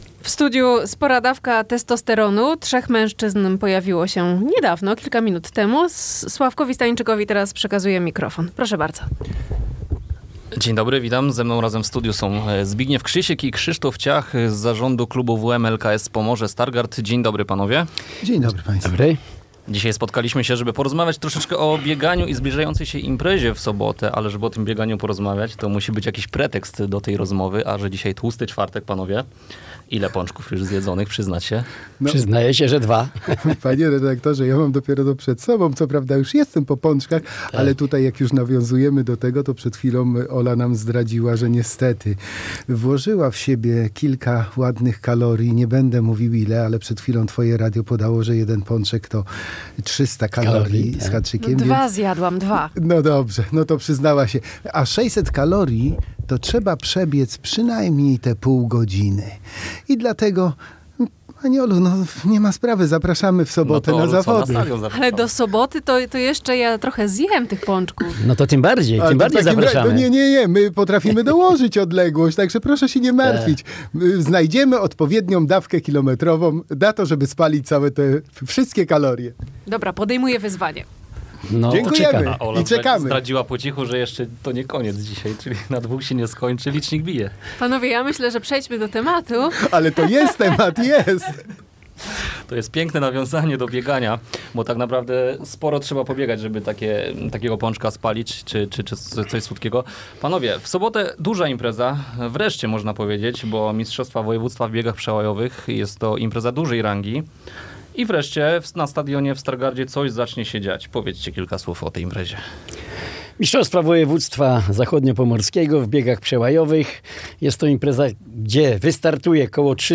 A poniżej do wysłuchania cała rozmowa